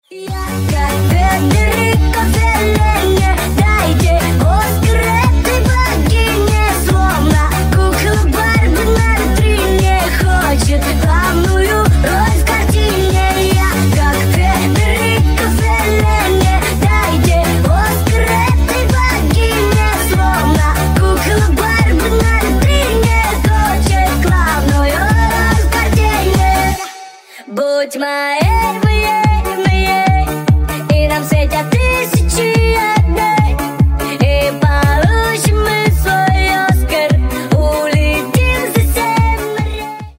Весёлые Рингтоны
Рингтоны Ремиксы » # Танцевальные Рингтоны